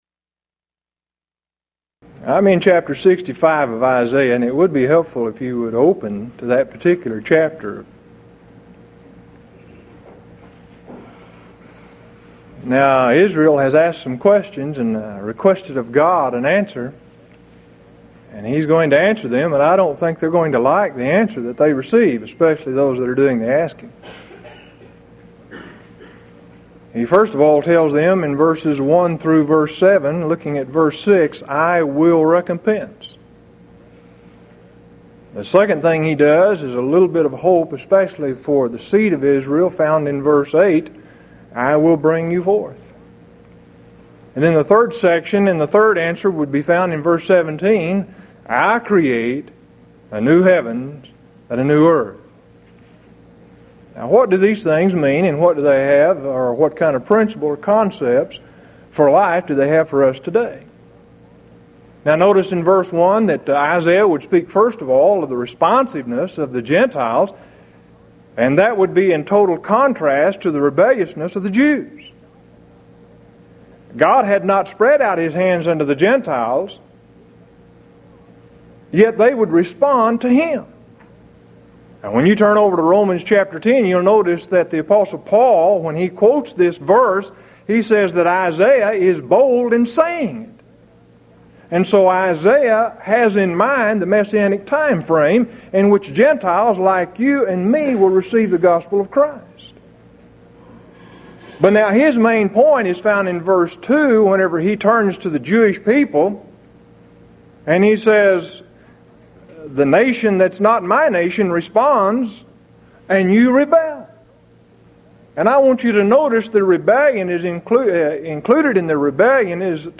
Series: Houston College of the Bible Lectures Event: 1996 HCB Lectures